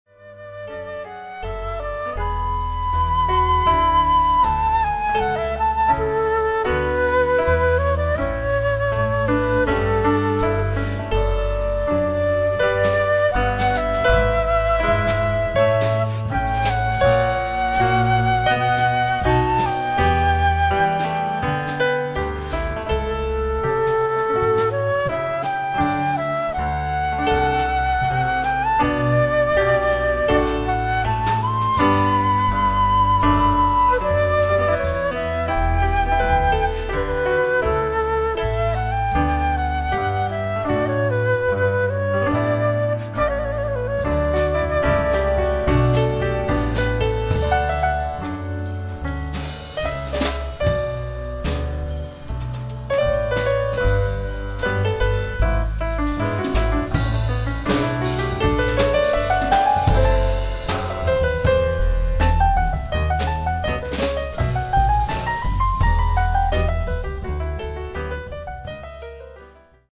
Live Performances